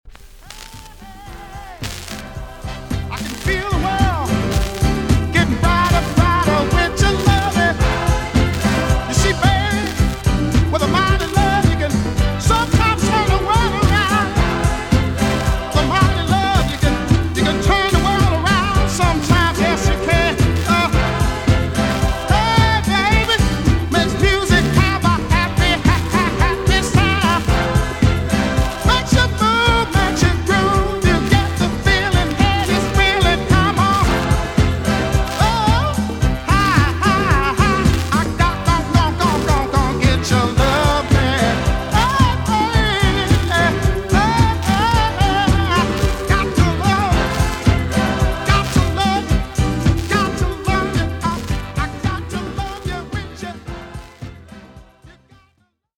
VG+~VG ok 軽いチリノイズが入ります。
1973 , WICKED SOUL CLASSIC TUNE!!